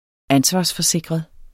Udtale [ ˈansvɑs- ]